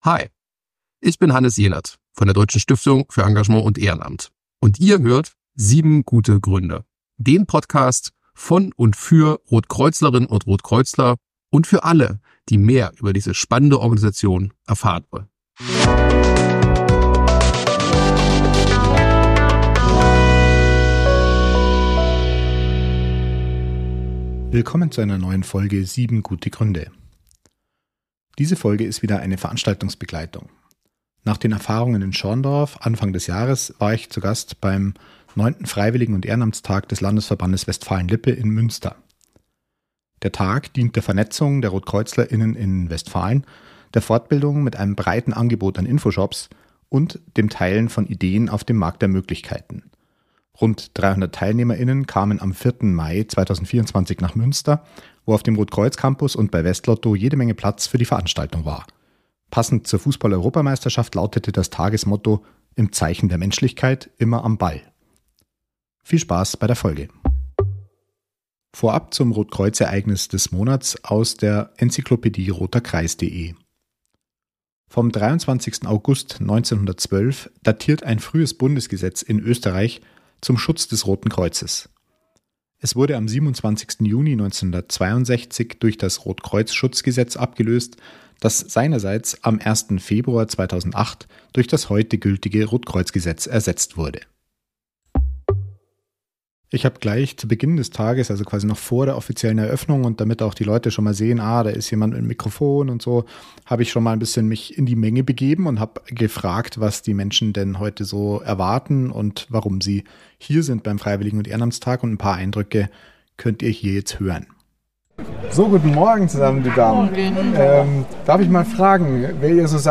Diese Folge ist wieder eine Veranstaltungsbegleitung.